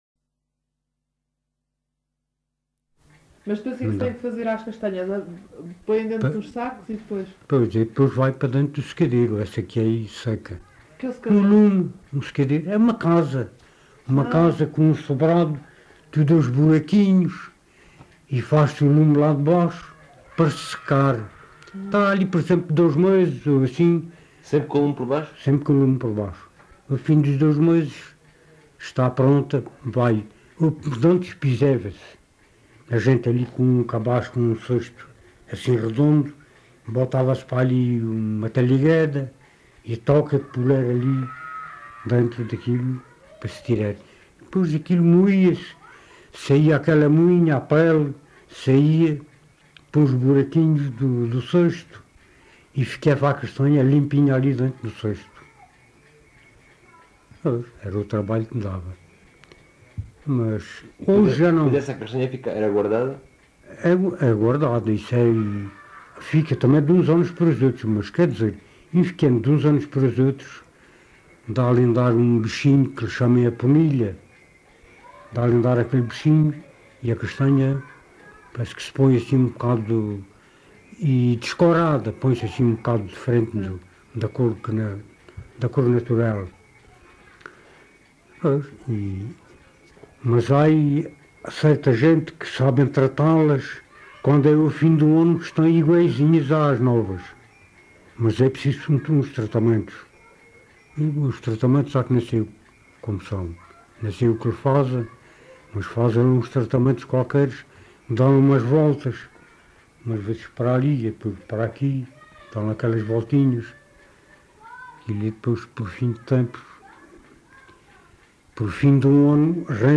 LocalidadePorto da Espada (Marvão, Portalegre)